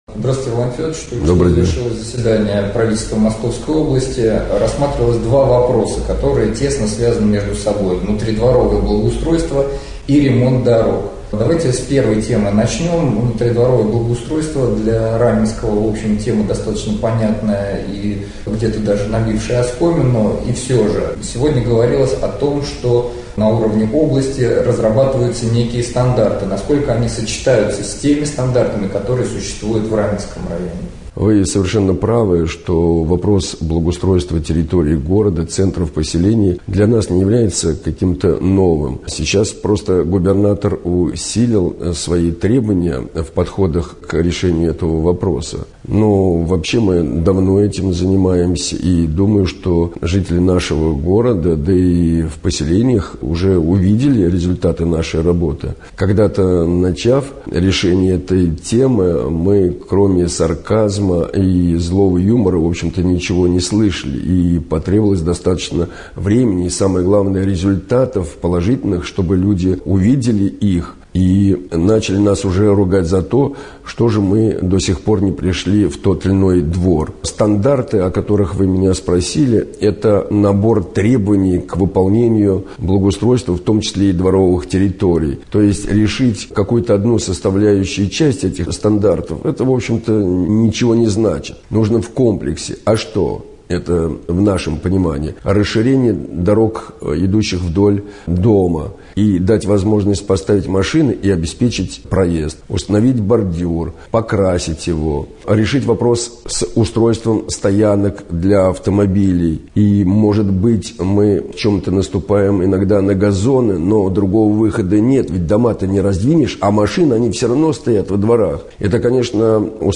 2. 21 октября состоялось очередное заседание правительства МО. В рамках совещания проходила видеоконференция с главами муниципальных образований. Об итогах этого совещания председатель комитета по СМИ К.А. Андреев побеседовал с главой Раменского муниципального района В.Ф.Деминым.